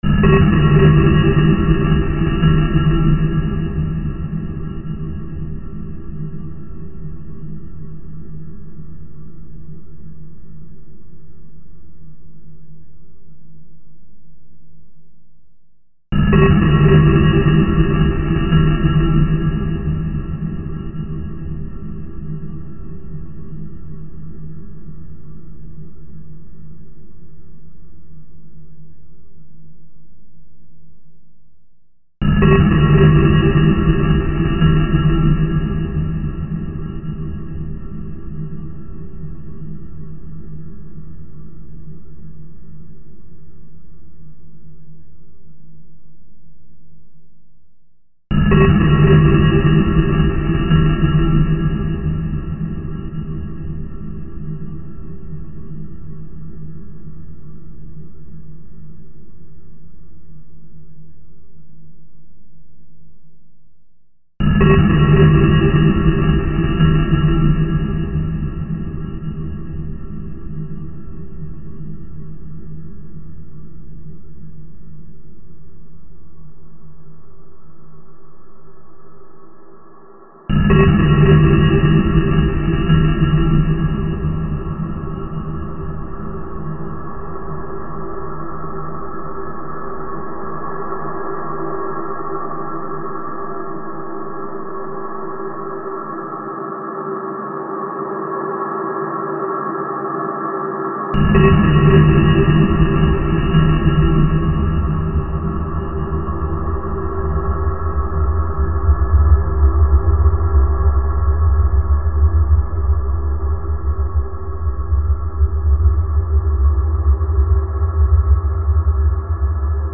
File under: Dark Ambient